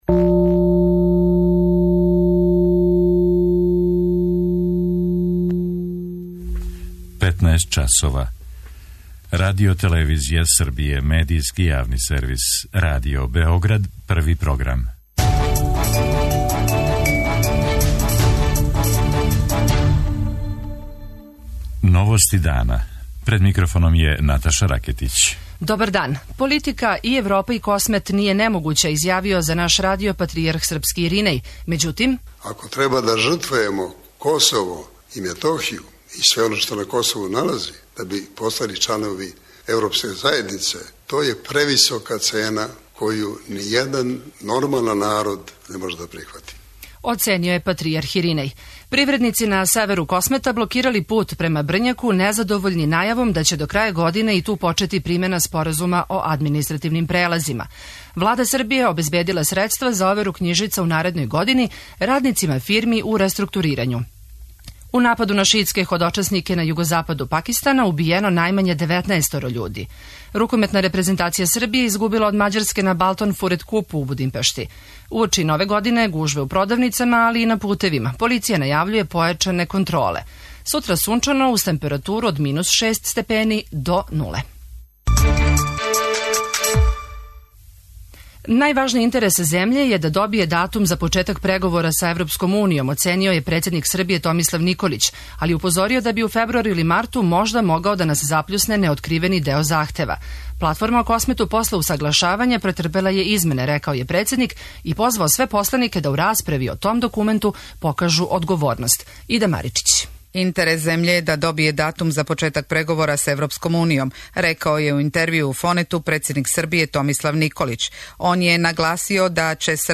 У емисији ћете чути шта је српски патријарх Иринеј, у интервјуу за Београд 1, говорио о ситуацији на Косову, као и о Платфорни за јужну српску покрајину.
преузми : 15.20 MB Новости дана Autor: Радио Београд 1 “Новости дана”, централна информативна емисија Првог програма Радио Београда емитује се од јесени 1958. године.